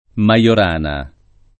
maLor#na] e Majorana [id.] cogn.